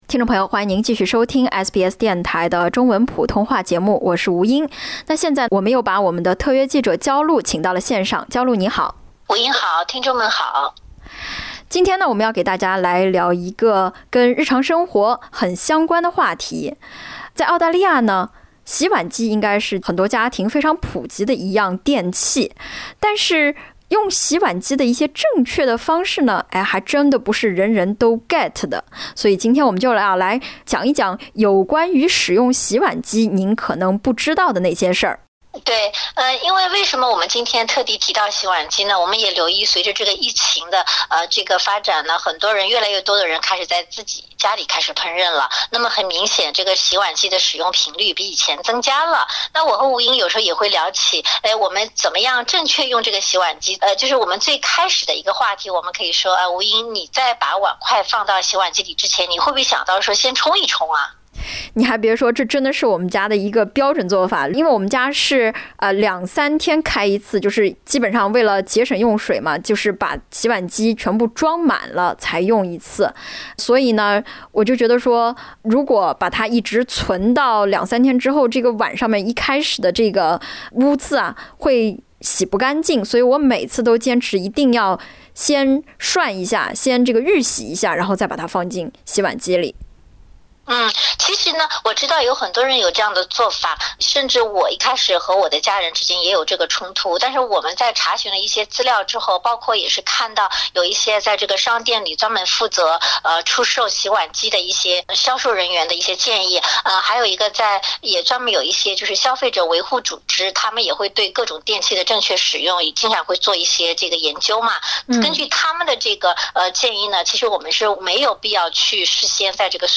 一场新冠疫情让很多人开始注意到洗碗机洗碗比手洗除菌效果更加出色。但是您可知道，错误使用洗碗机——比如先冲掉碗碟表面的残渣再交给机器“对付”不一定能把餐具洗得更干净，搞不好还会适得其反（点击封面图片收听完整采访）。